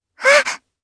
Lavril-Vox_Damage_jp_01.wav